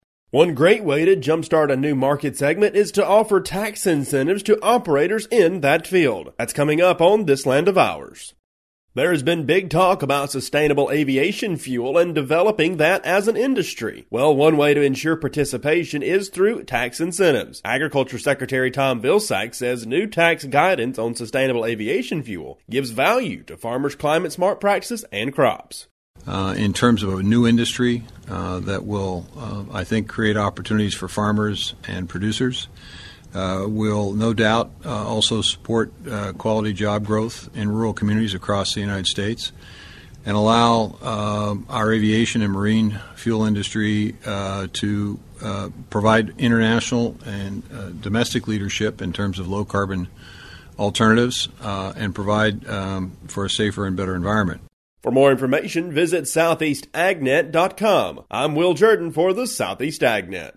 reports with Agriculture Secretary Tom Vilsack